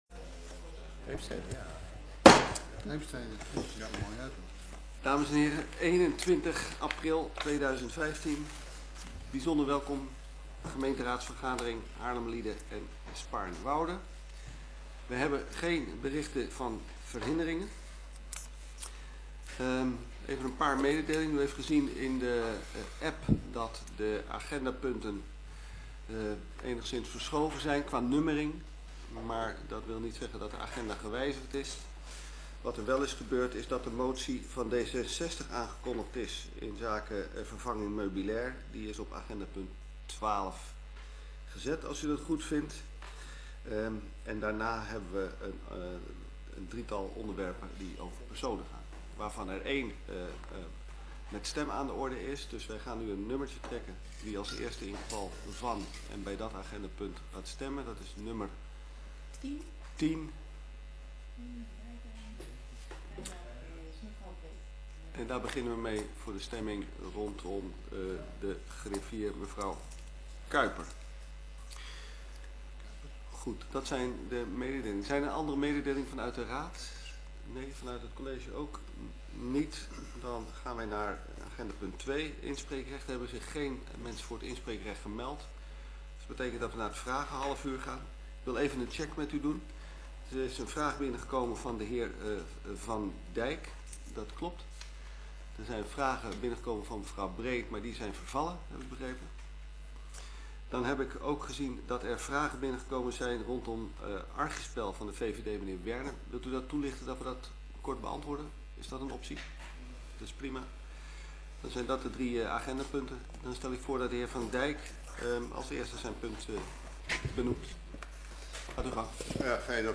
Gemeenteraad 21 april 2015 20:00:00, Gemeente Haarlemmermliede
Reguliere vergadering.
Locatie: Raadzaal